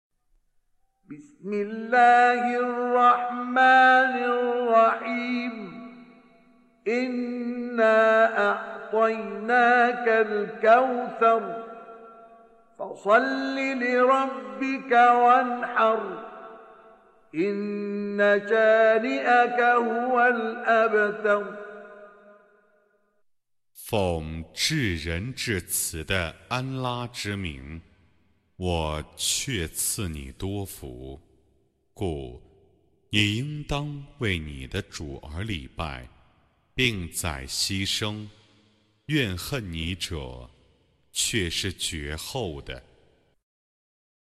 Reciting Mutarjamah Translation Audio for 108. Surah Al-Kauthar سورة الكوثر N.B *Surah Includes Al-Basmalah